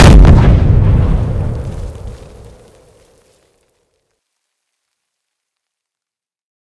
large-explosion-2.ogg